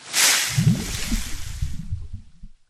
acidic_hit.ogg